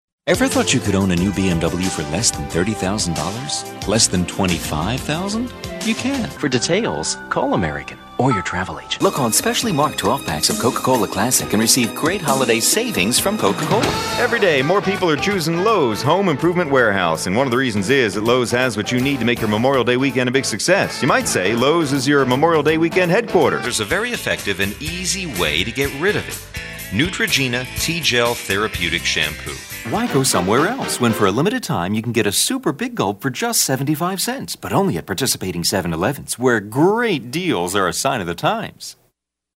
Commercial Demo
English - USA and Canada
Middle Aged